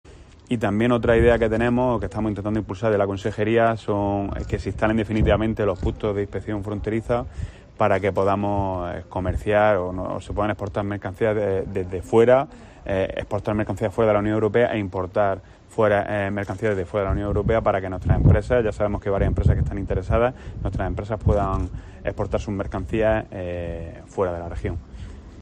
José Manuel Pancorbo, consejero de Fomento e Infraestructuras